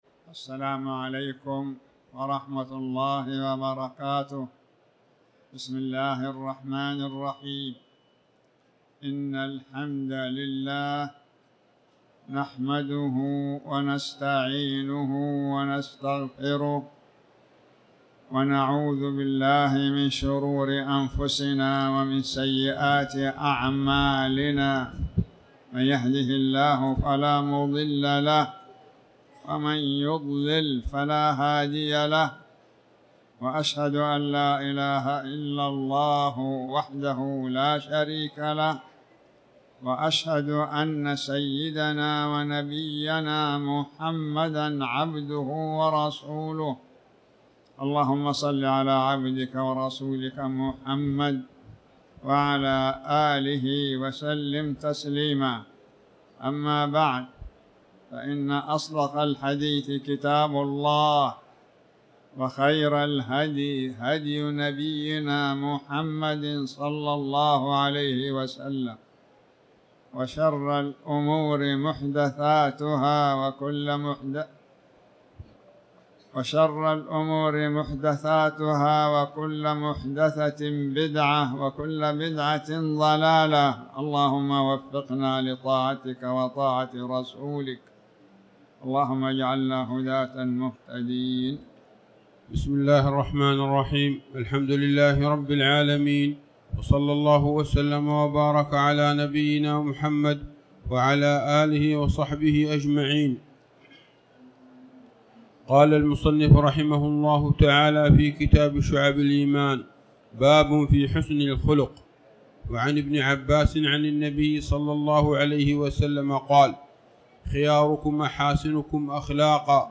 تاريخ النشر ١٠ رجب ١٤٤٠ هـ المكان: المسجد الحرام الشيخ